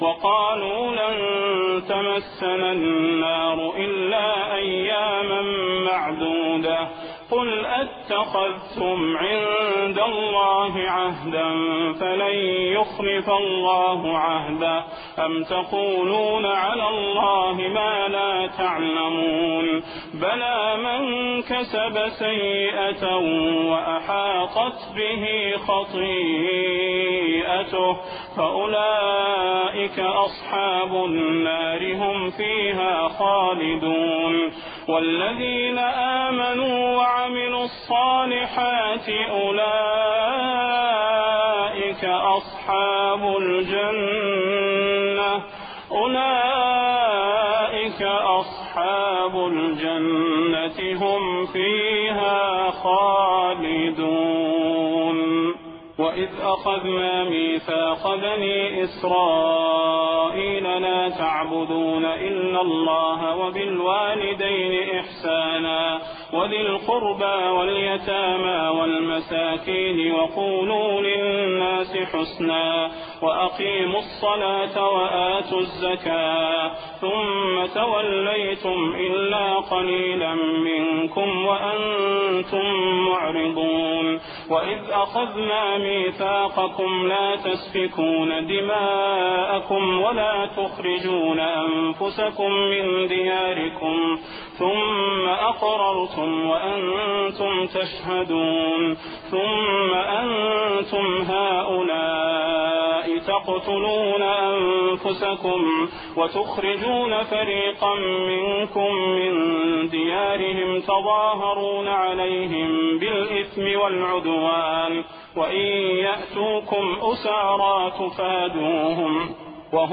البقرة 80-86 البدير تهجد 1420